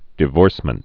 (dĭ-vôrsmənt)